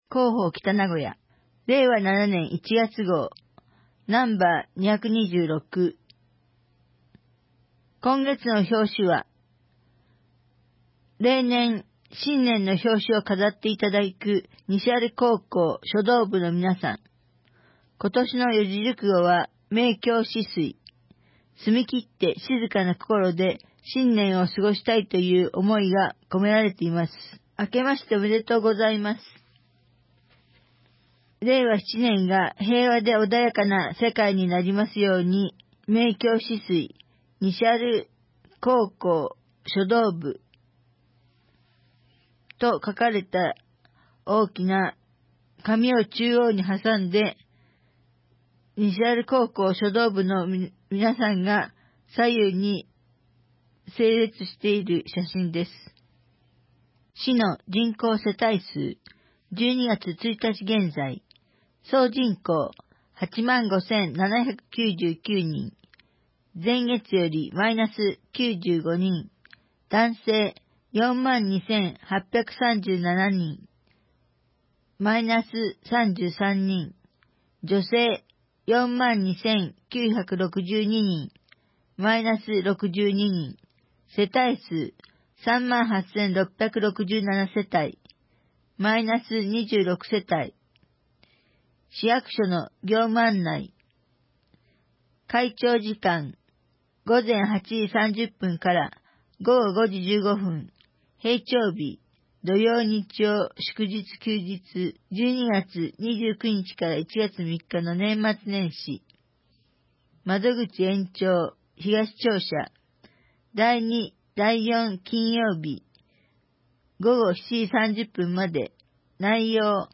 2025年1月号「広報北名古屋」音声版